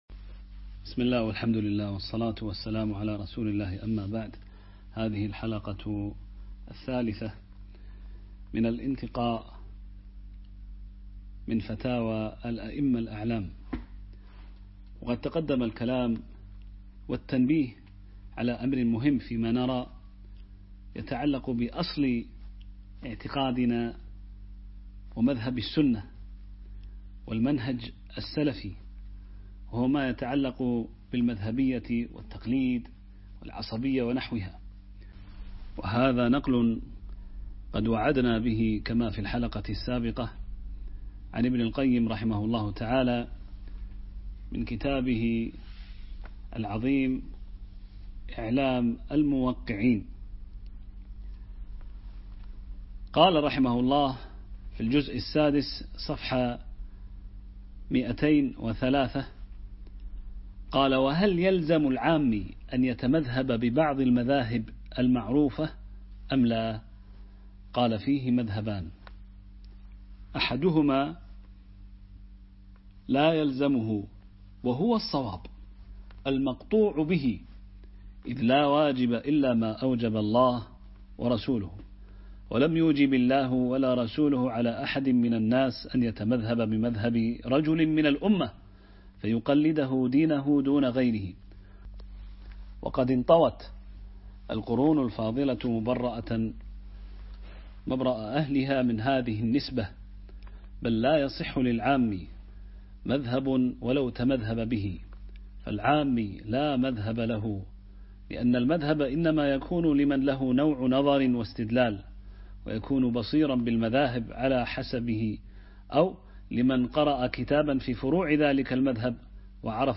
مختارات من فتاوى الأئمة الأعلام - الدرس الثالث